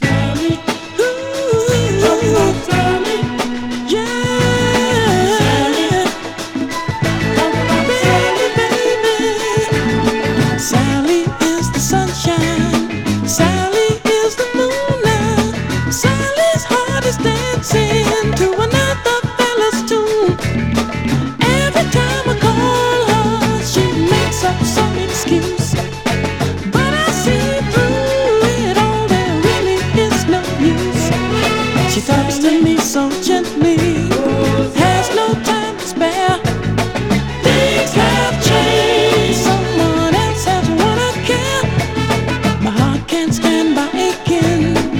Soul　USA　12inchレコード　33rpm　Stereo